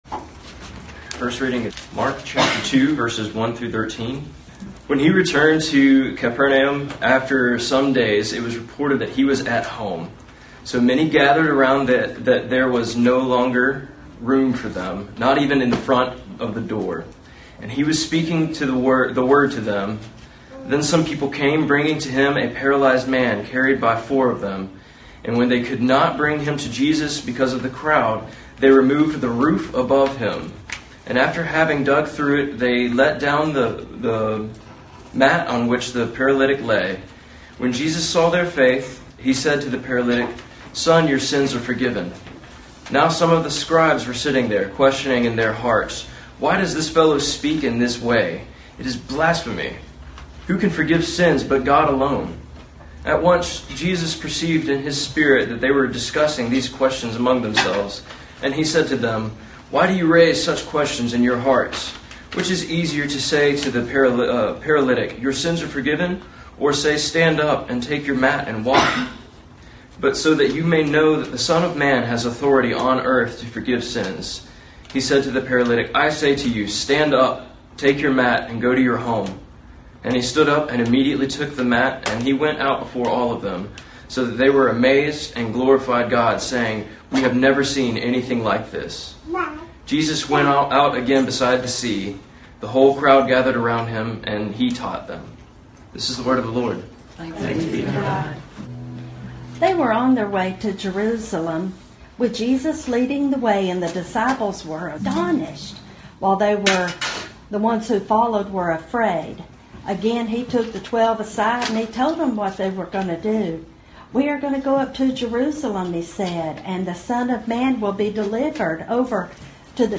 Passage: Mark 10:32-45 Service Type: Sunday Morning